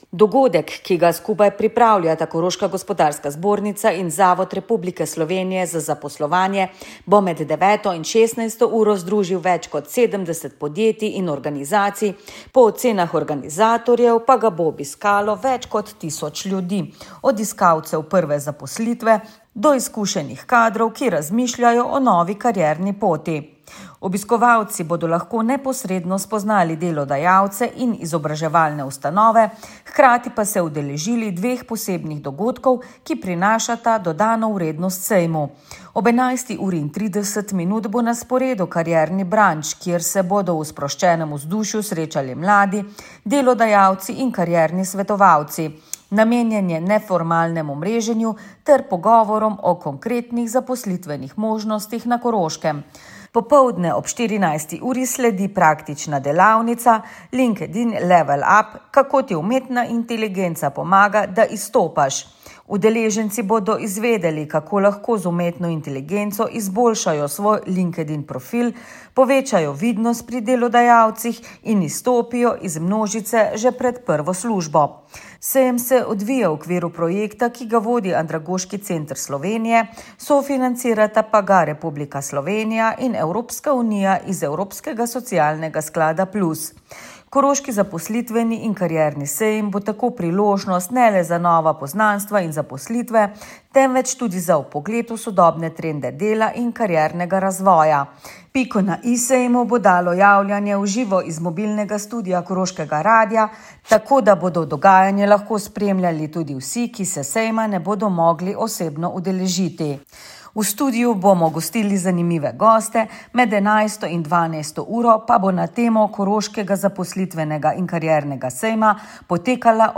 Tam je tudi naša ekipa z mobilnim studiem.